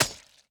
Sword Impact Hit 3.ogg